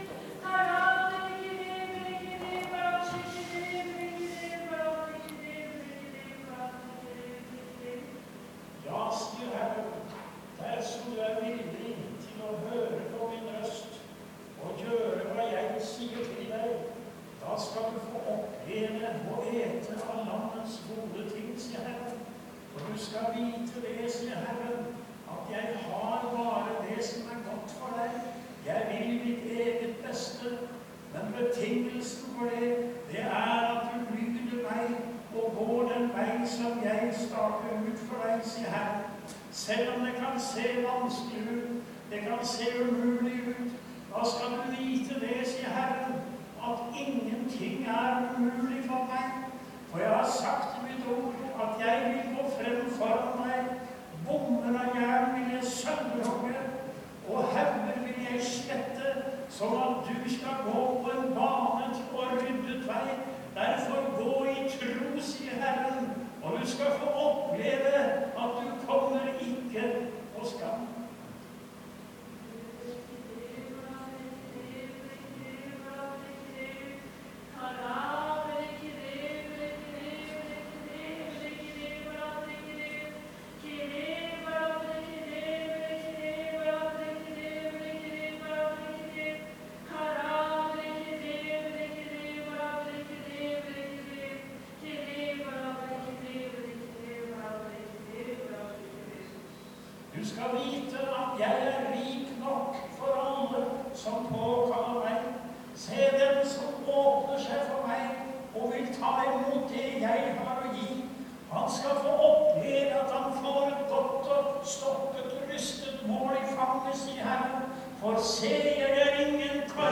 Gå den vegen som Jesus stakar ut for meg, han går føre meg og banar vegen for meg. Møte i Maranata 4.5.2014.